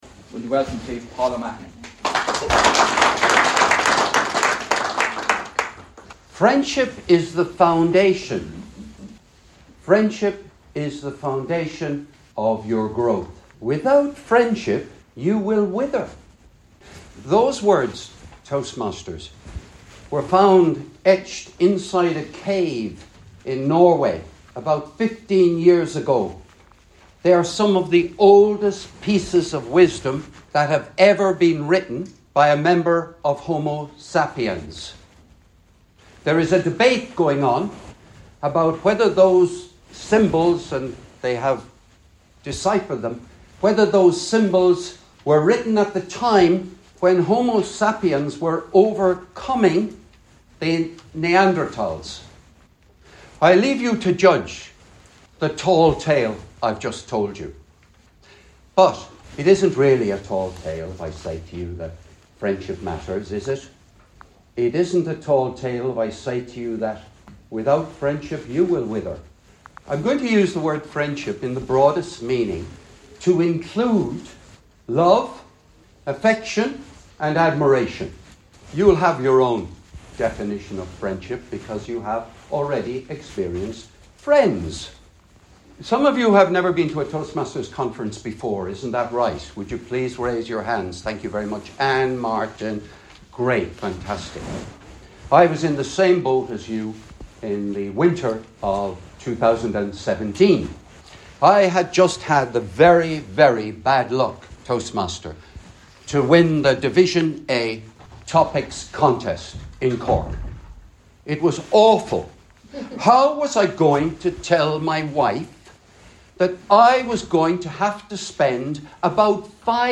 a speech
to the Anglo-Irish meeting at the annual conference of District 71 (Ireland & UK north) in Portlaoise on Thursday 9th May 2024.